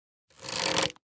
Minecraft Version Minecraft Version snapshot Latest Release | Latest Snapshot snapshot / assets / minecraft / sounds / item / crossbow / quick_charge / quick1_2.ogg Compare With Compare With Latest Release | Latest Snapshot